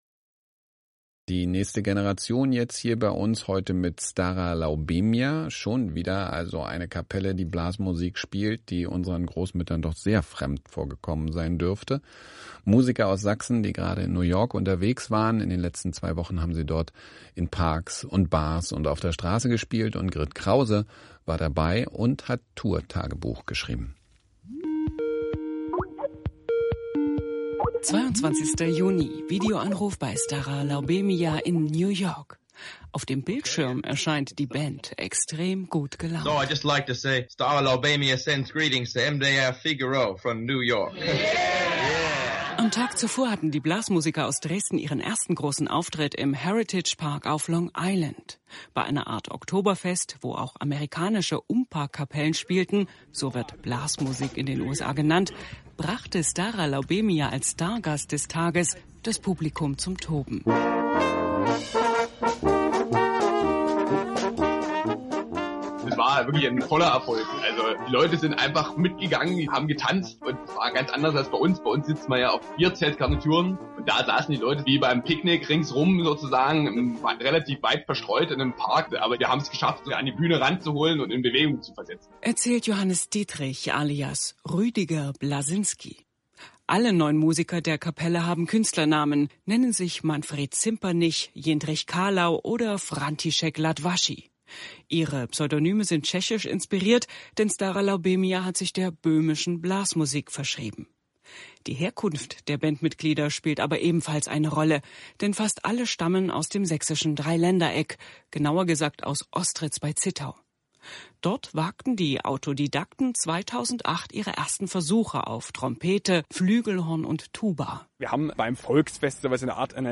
Radio-Porträt von Stara Laubemia anlässlich unserer New York Tour, hier zum nachzuhören:
Radio-Portrait-MDR-Figao_Juli2014.mp3